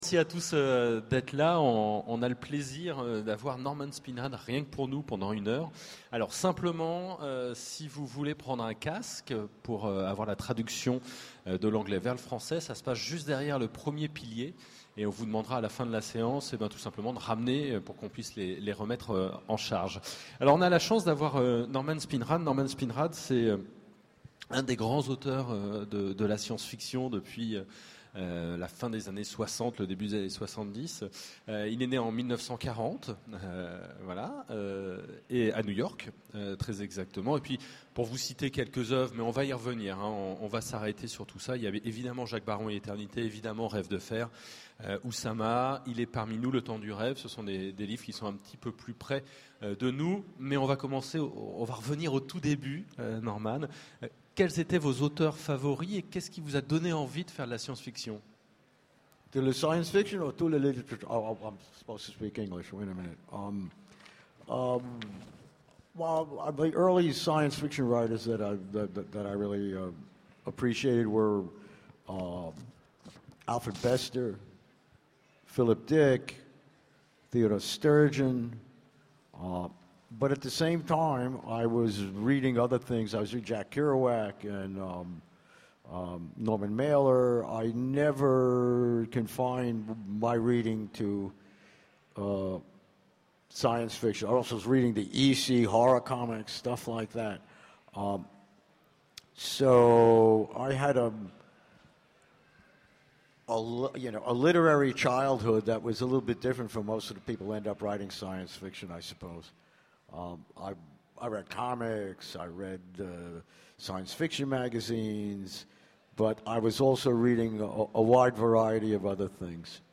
Utopiales 12 : Conférence Rencontre avec Norman Spinrad
Utopiales2012Norman.mp3